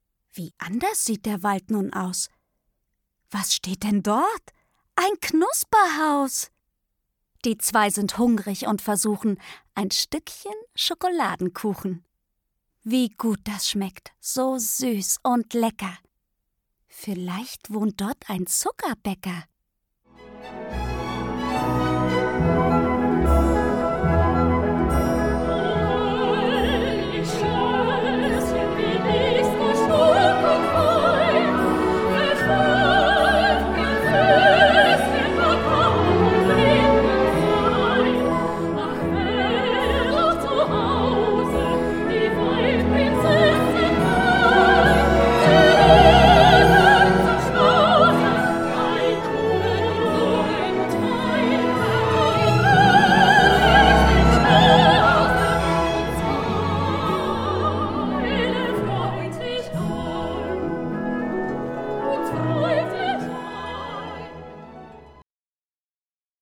Meine Musikmärchen - Hänsel und Gretel Märchen, Reime und Musik. Ein zauberhaftes Pappbilderbuch für Kinder ab 3 Jahren Cornelia Boese (Autor) Josefine Preuß (Sprecher) Buch | Kinder-Pappbuch 26 Seiten 2025 | 1.